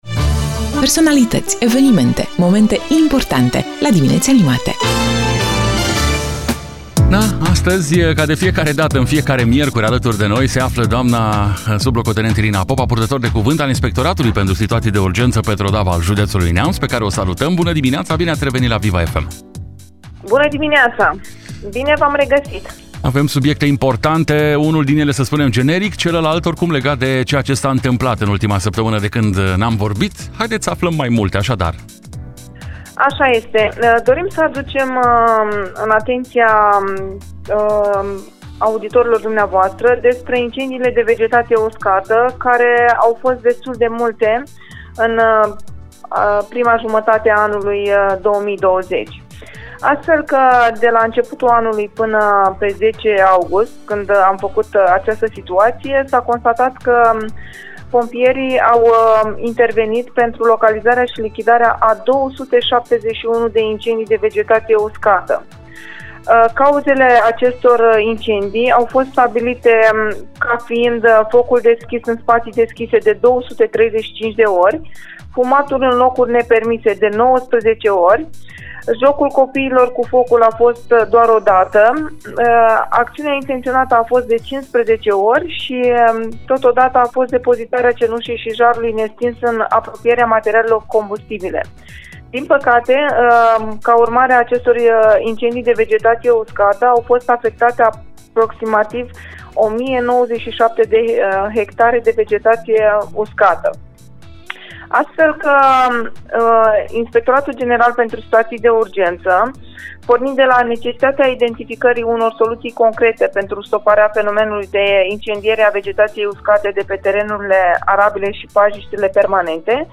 Amănunte ne-a oferit în direct prin telefon la Dimineți Animate de pe Viva FM Neamț